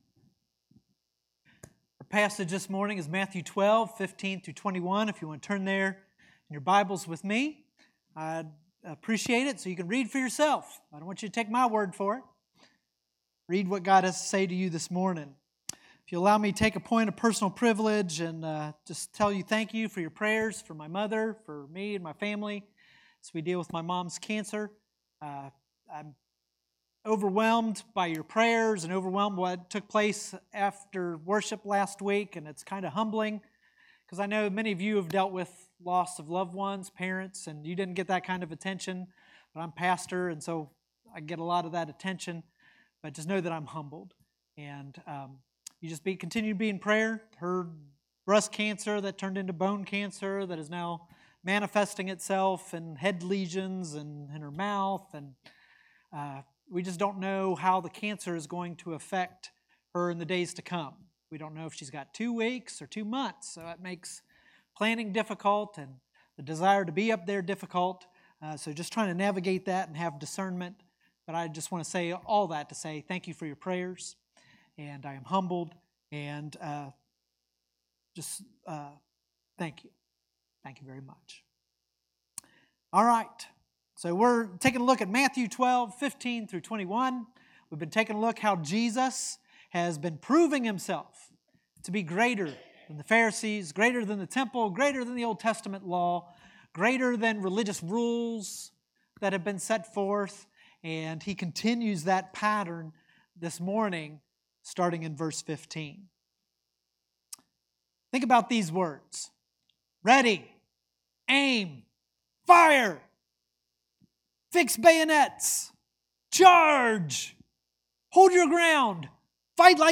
Sermons | Flint Hill Baptist Church